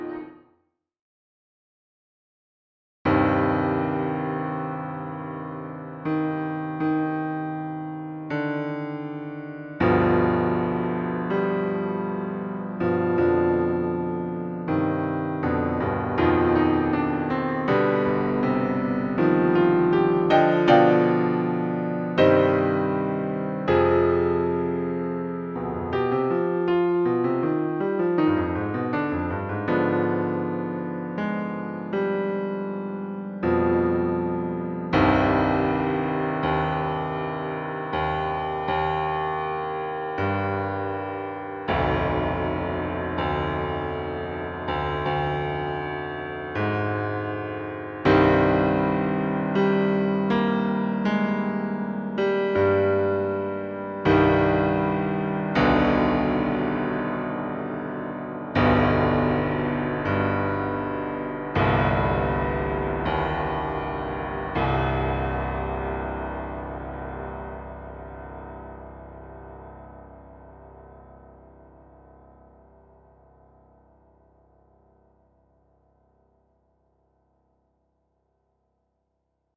Op.24 - Piano Music, Solo Keyboard - Young Composers Music Forum